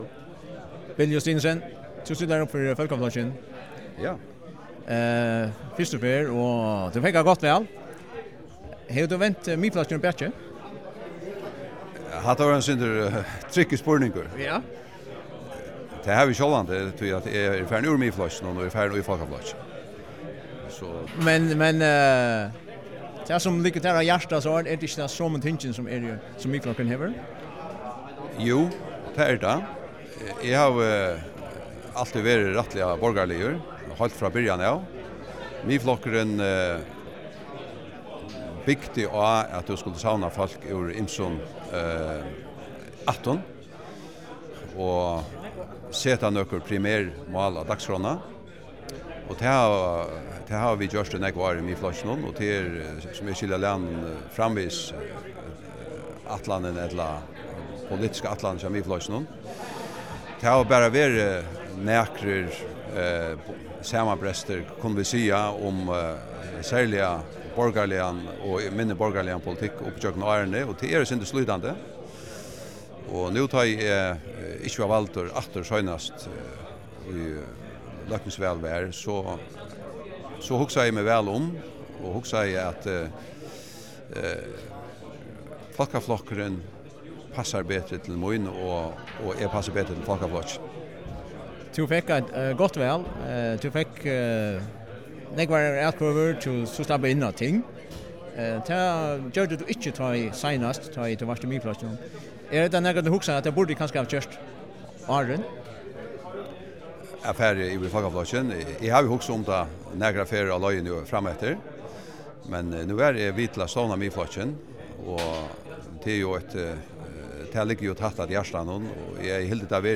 Hoyr Bill Justinussen greiða frá m.a. hví hann fór úr Miðflokkinum og yvir í Fólkaflokkin.